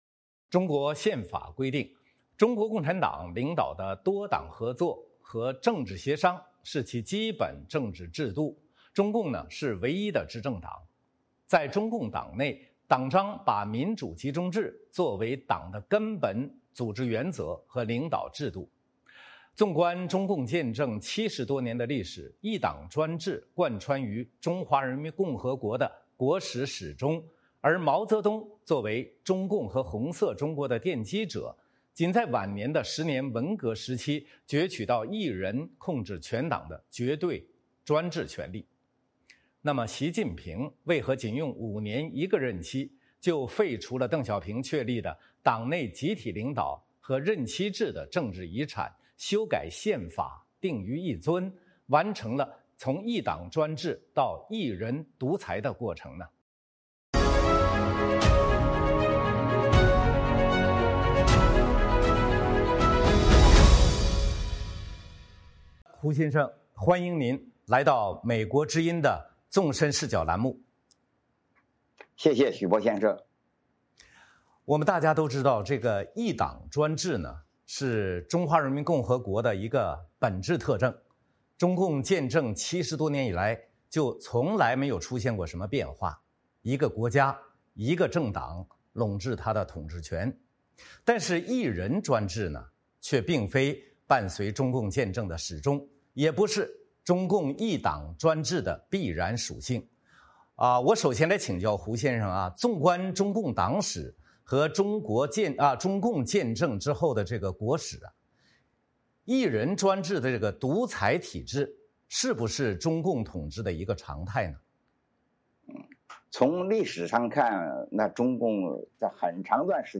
专访胡平：从一党到一人，习近平如何登上专制巅峰
习近平为何仅用5年一个任期，就废除了邓小平确立的党内集体领导和任期制的政治遗产，修改宪法，定于一尊，完成了从一党专制到一人独裁的过程？ 《纵深视角》节目进行一系列人物专访，受访者所发表的评论并不代表美国之音的立场